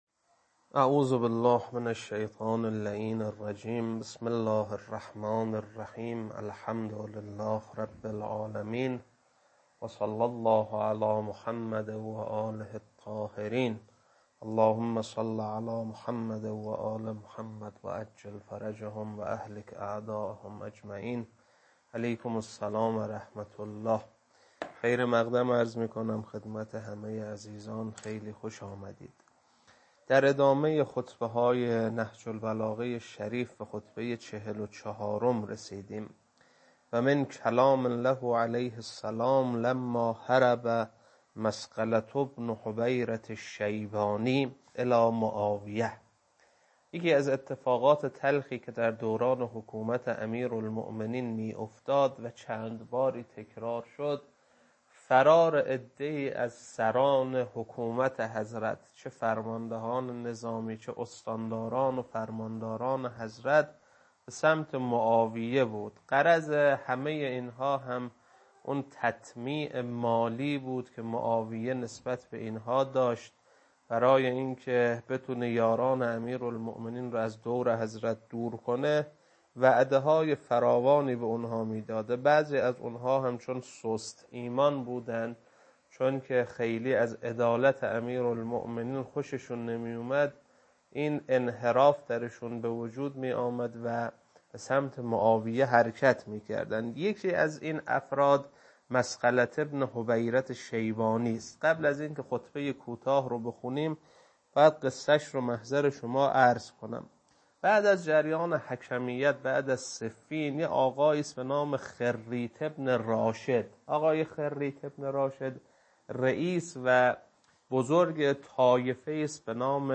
خطبه 44.mp3